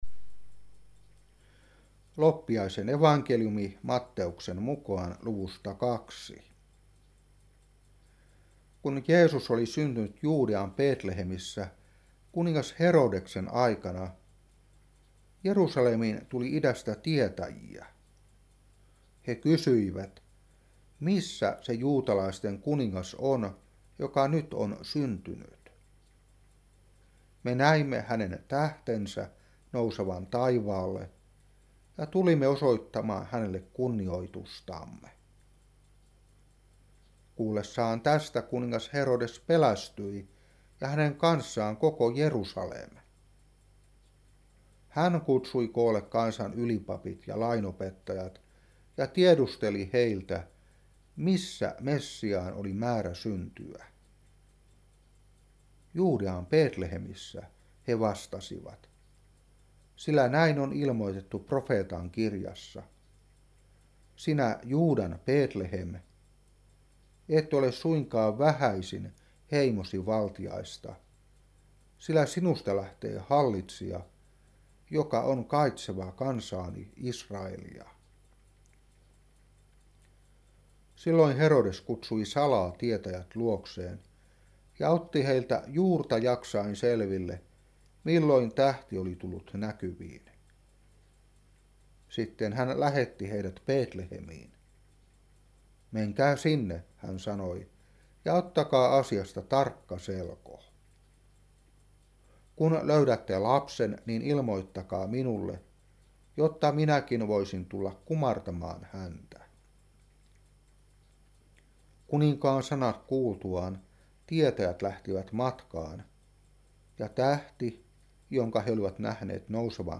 Saarna 2002-1.